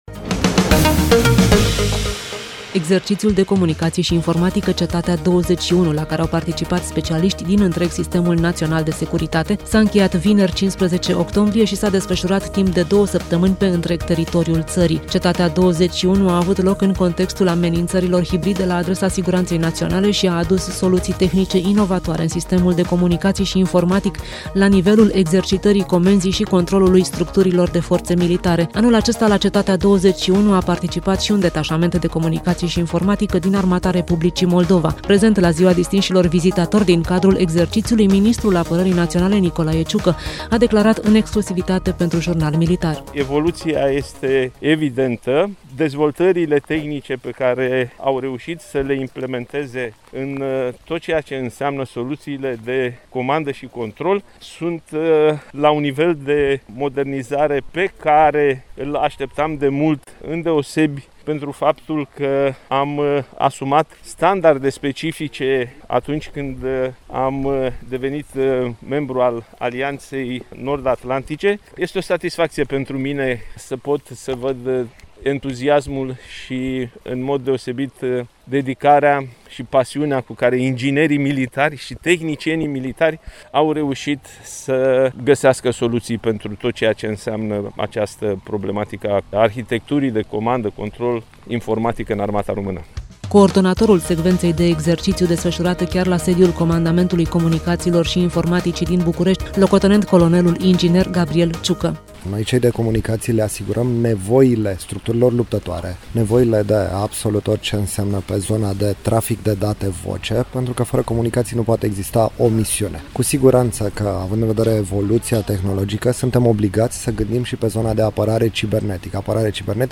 3Cetatea insert radio.mp3